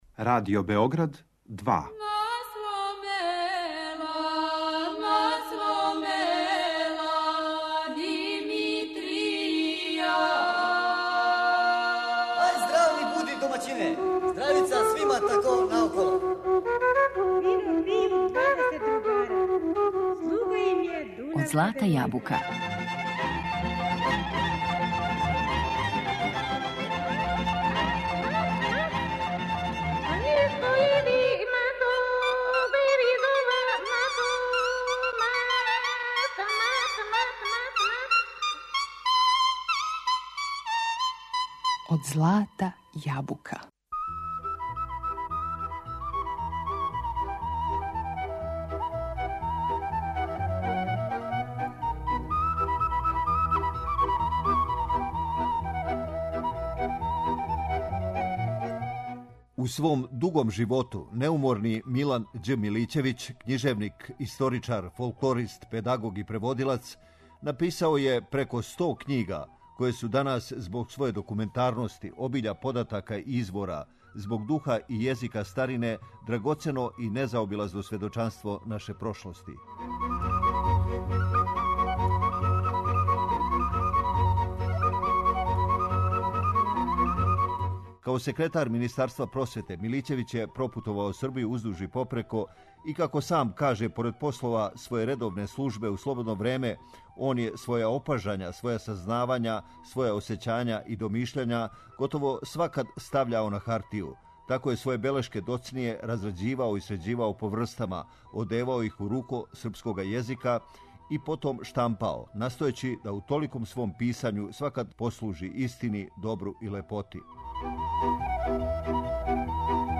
Емисија је резервисана за изворну народну музику.